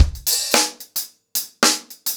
DaveAndMe-110BPM.27.wav